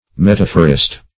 Metaphorist \Met"a*phor*ist\, n. One who makes metaphors.